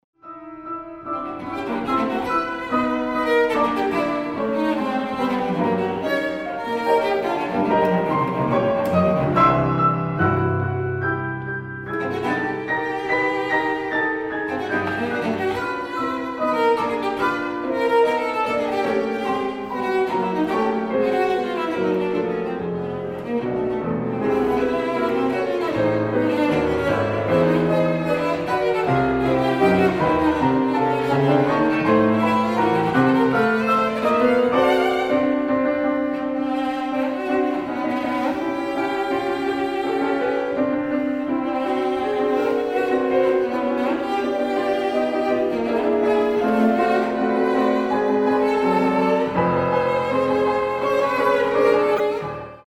大提琴
鋼琴
使用骨董真空管麥克風錄音，並以自製的真空管混音台混音